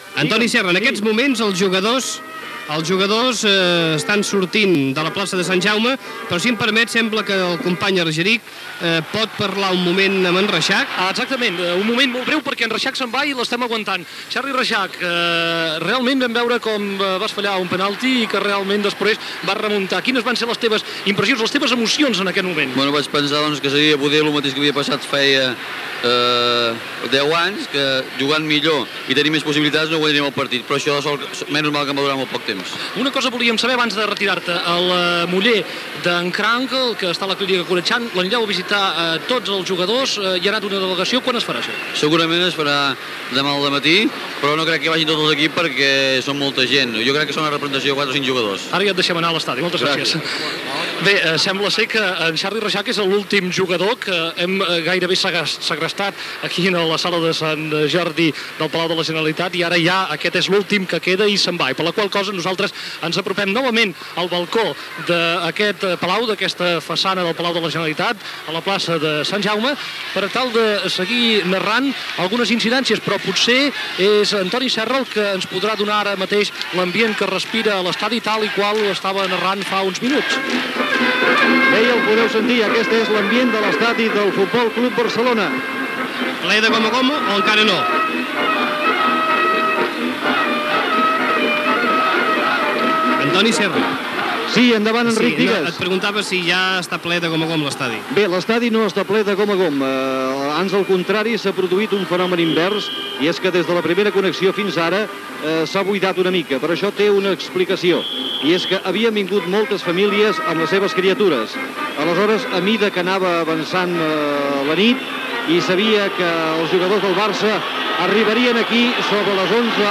Transmissió de l'arribada del F:C: Barcelona amb la recopa d'Europa de futbol masculí.
Ambient als carrers de Barcelona (Via Laietana)
Informatiu